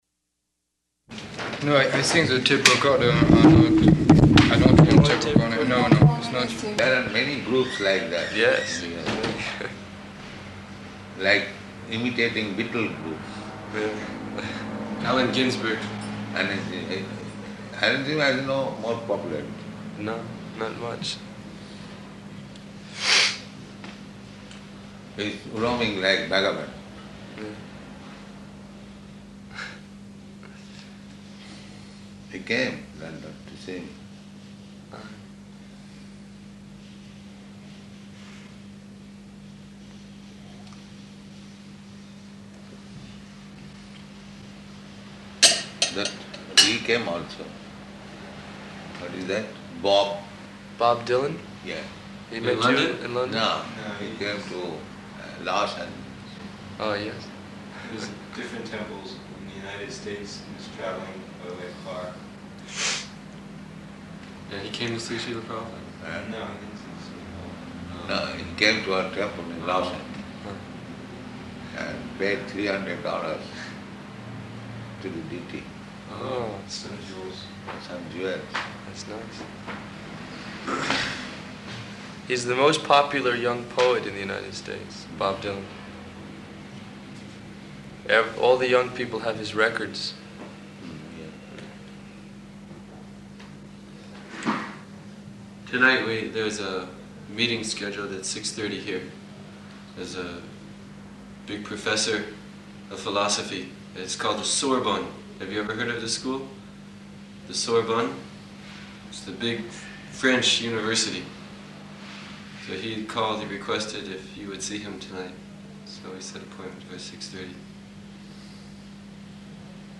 Room Conversation with French Journalist --:-- --:-- Type: Conversation Dated: August 10th 1973 Location: Paris Audio file: 730810R2.PAR.mp3 French devotee: No, these things are typical, er...